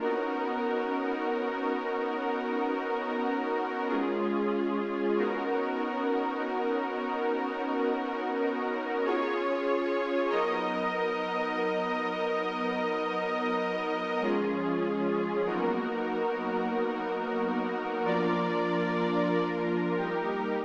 08 pad C.wav